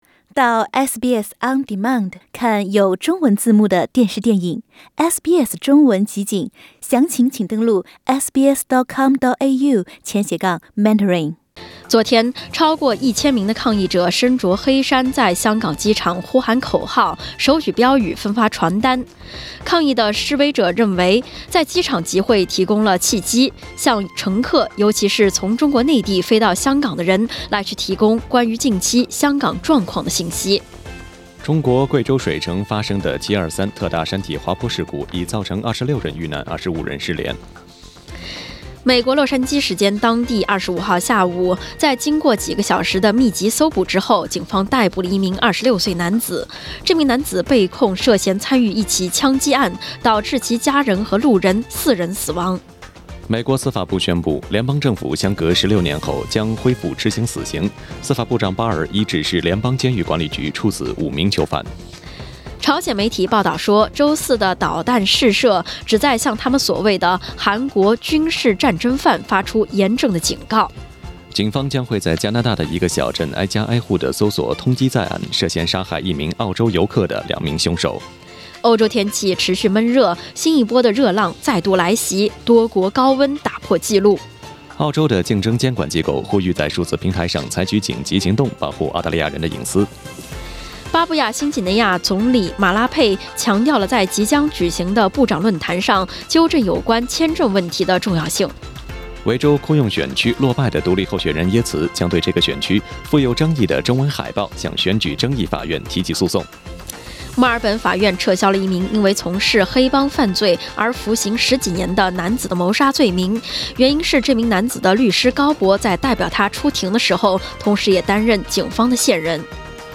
SBS早新闻（7月27日）